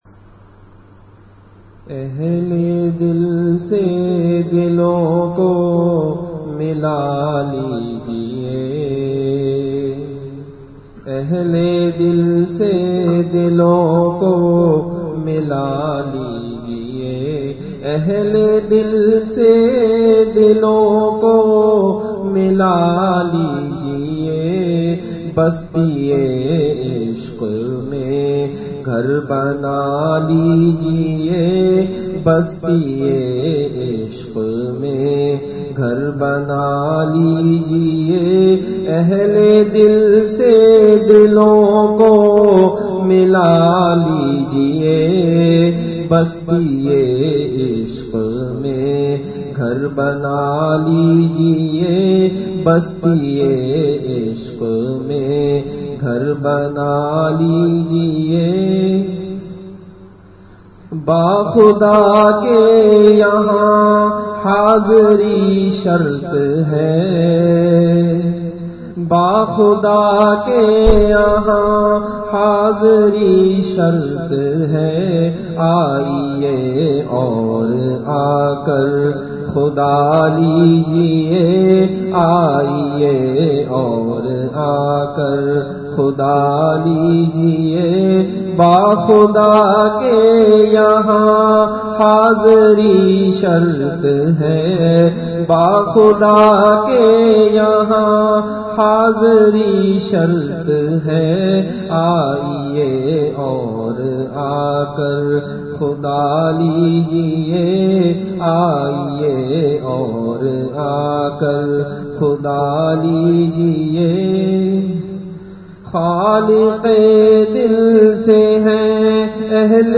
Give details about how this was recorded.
Event / Time After Isha Prayer